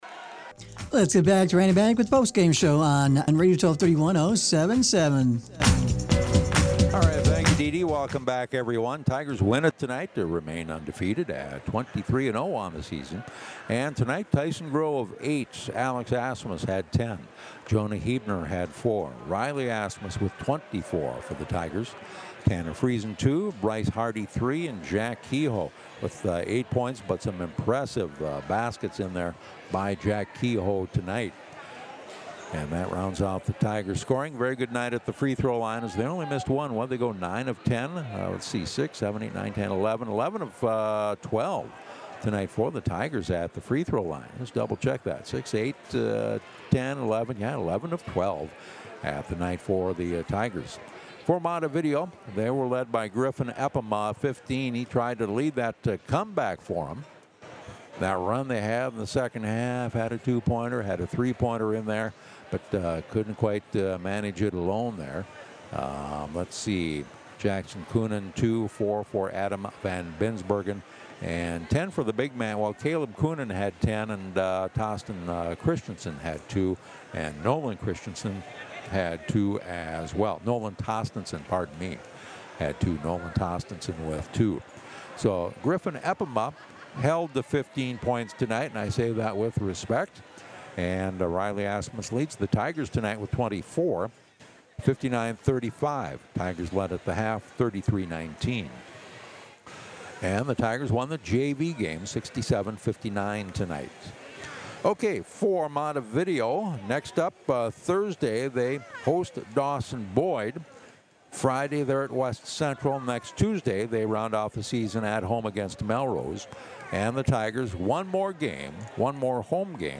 TIGER POST GAME ON KMRS